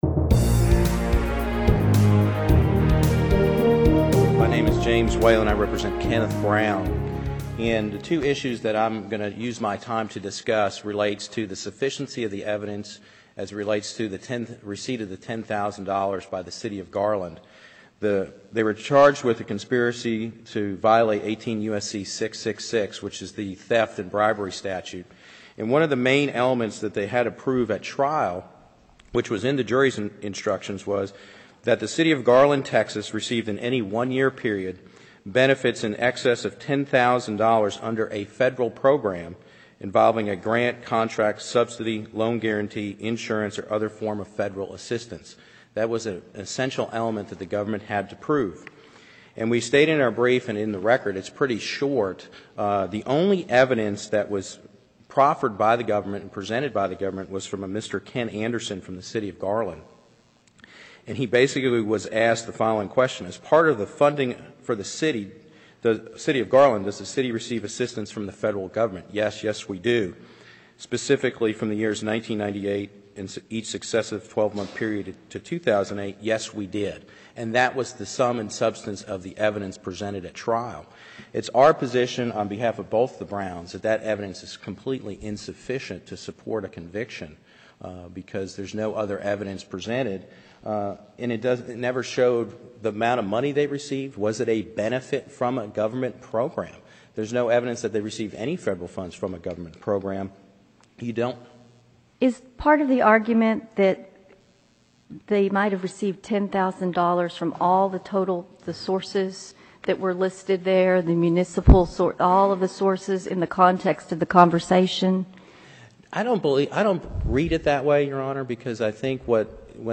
Audio Recordings of Oral Arguments
You can learn a lot about federal law by hearing defense attorneys arguing their cases in Federal Court.